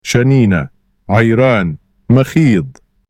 تلفظ شَنِينَة: (شَـنیـنَه) با تاکید روی حرف نون و کشیدگی ی. کاربرد: معمولاً به عنوان یک نوشیدنی خنک در کنار غذاهای سنتی یا به تنهایی مصرف می‌شود.